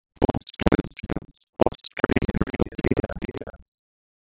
After doing my research the main response I found was that since the front tires hit the grooves a split second before the rear tires, it produces a strange echo. This echo, if in a male voice, is said to sound like God talking to you, which is another reason Firestone bailed on the idea.
Here is a sound clip of what I think the voice would sound like if we used a Foster's beer ad.